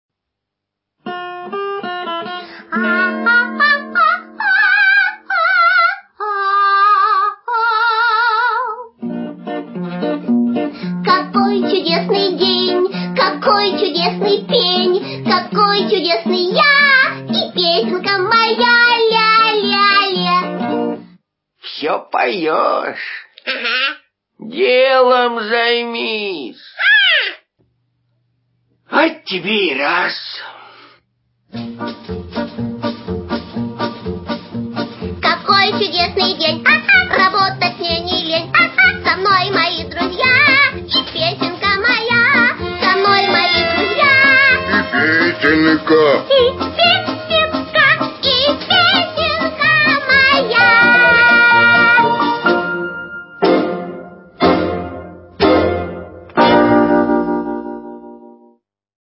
очень позитивный будильник